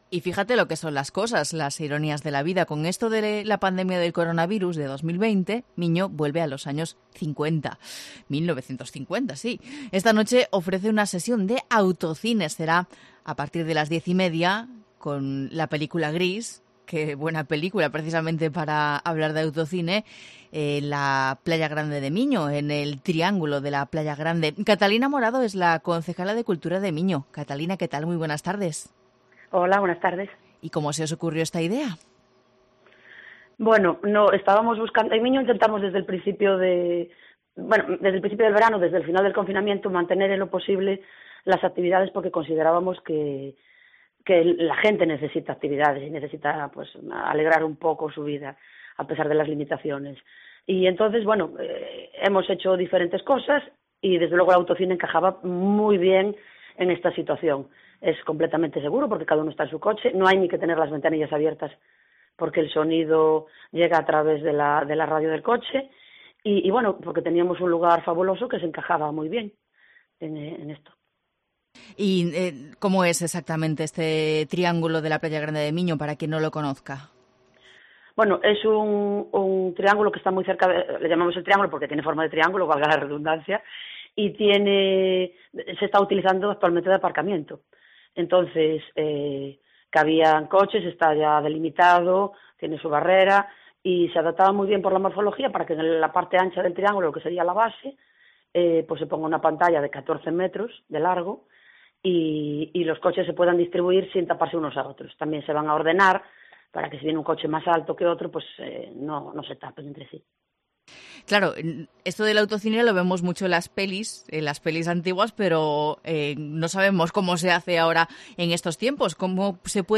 Entrevista a Catalina Morado, concejala de Cultura de Miño